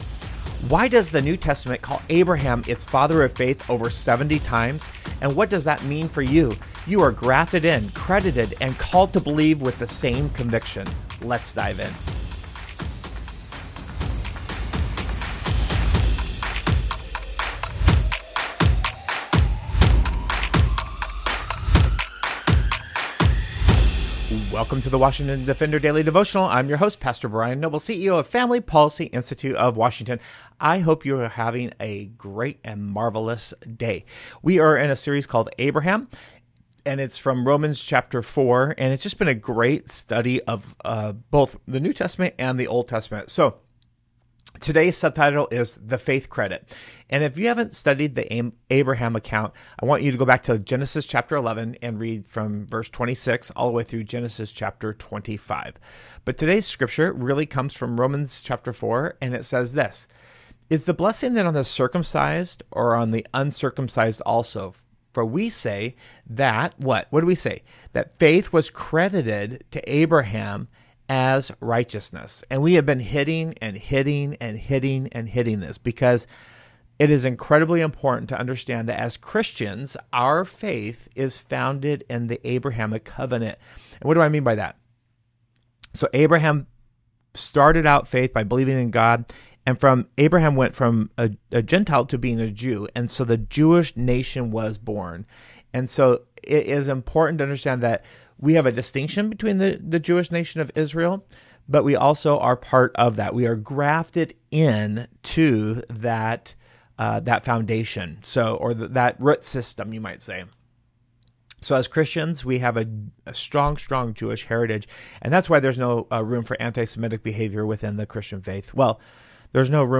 A Devotion for your drive into work: